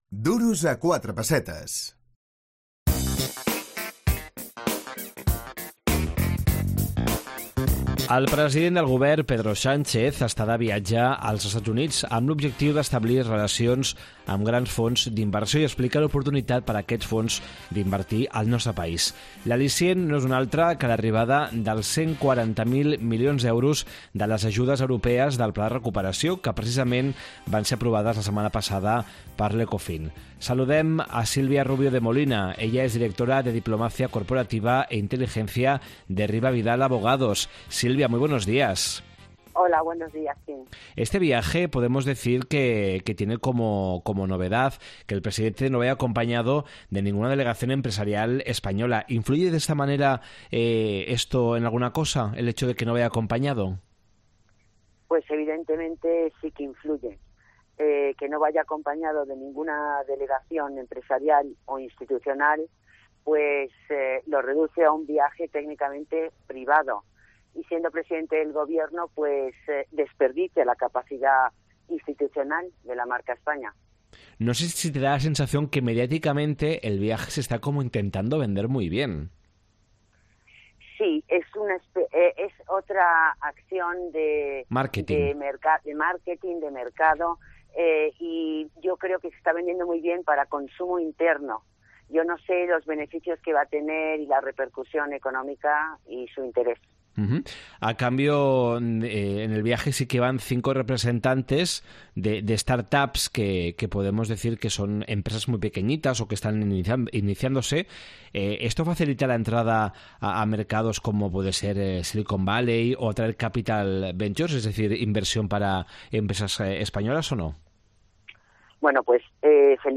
Valoració del viatge del president del Govern als Estats Units. Entrevista